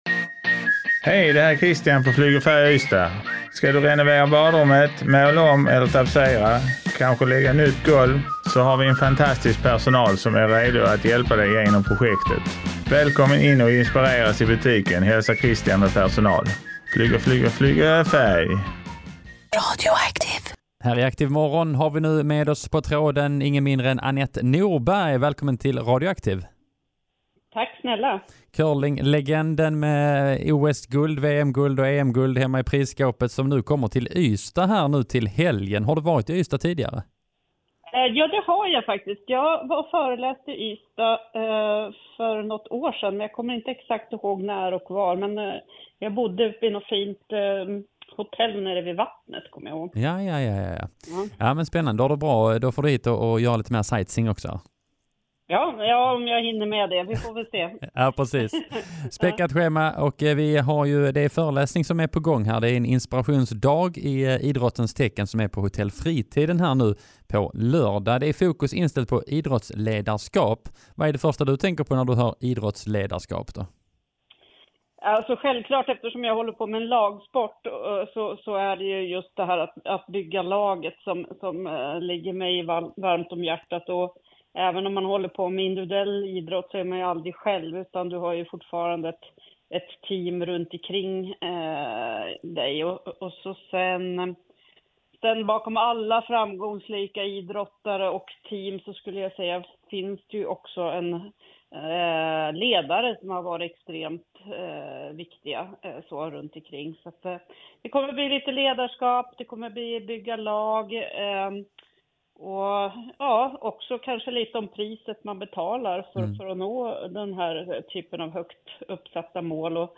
Curlinglegenden Annette Norberg gästade ActiveMorron där vi pratade om hennes besök i Ystad till helgen där hon ska föreläsa.